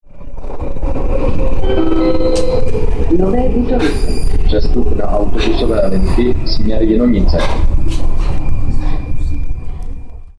Informovanost cestujících je zajištěna formou hlášení přímo v soupravách metra.
- Hlášení po příjezdu do stanice Nové Butovice (směr Zličín) si